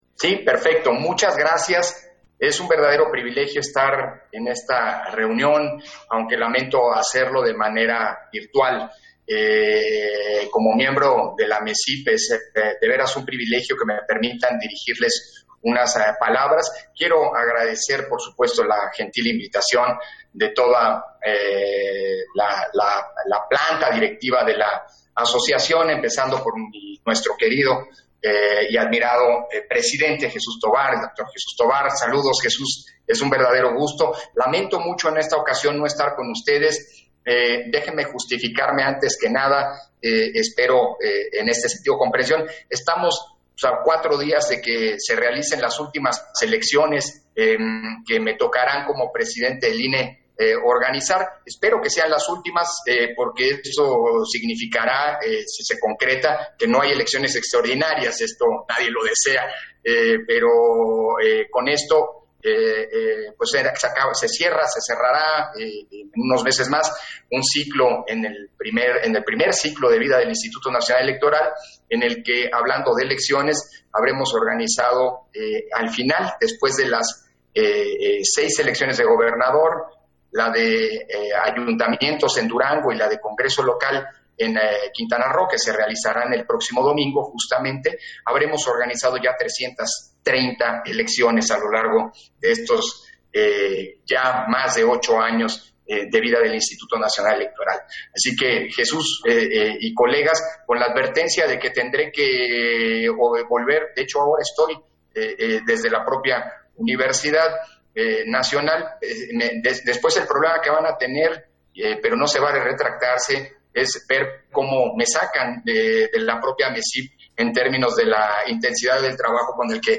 010622_AUDIO-INTERVENCIÓN-CONSEJERO-PDTE.-CÓRDOVA-AMECIP - Central Electoral